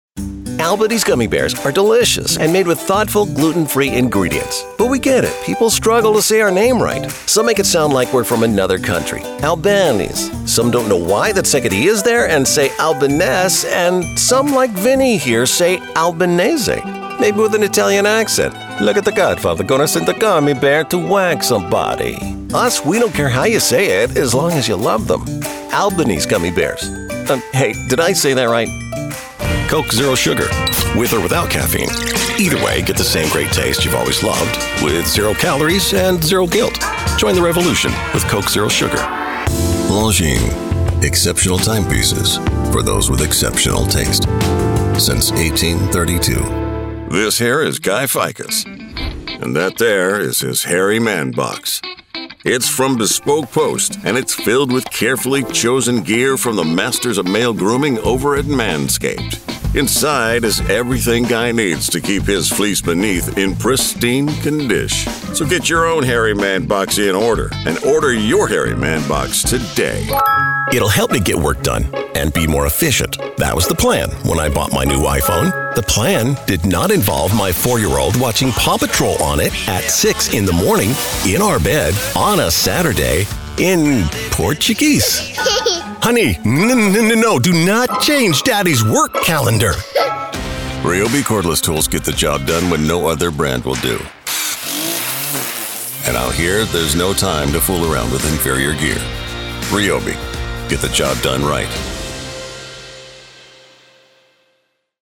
Commercial, branding, training & narration.